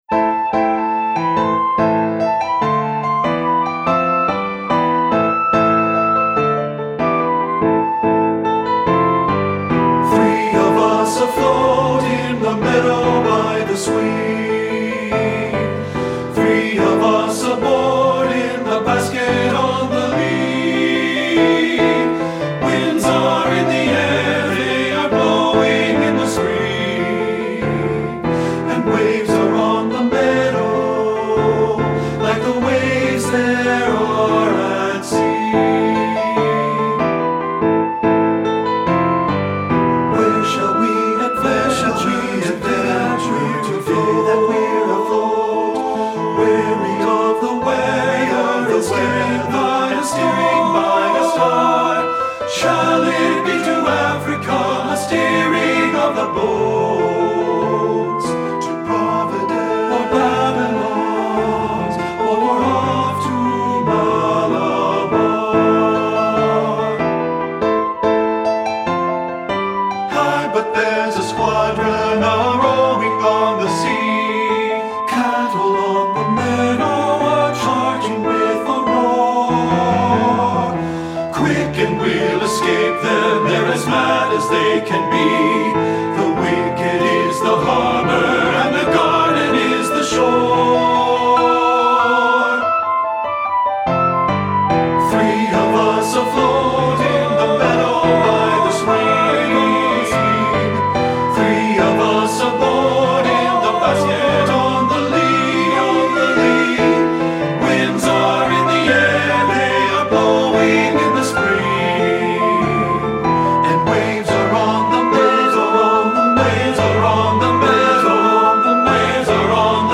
Voicing: TB(B) and Piano